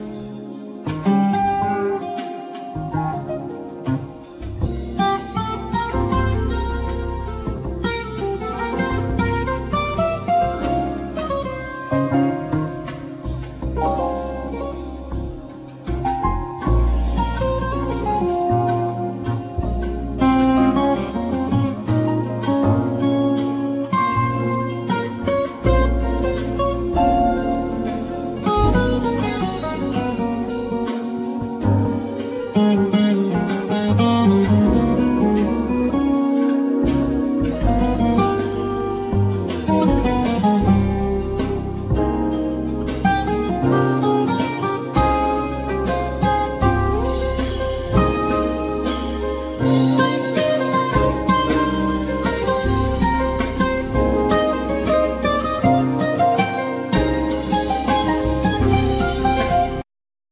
Classical&12 String Guitar,Synthsizer,Piano
Bass
Peucussions,Vocal
Drums,Hand Drums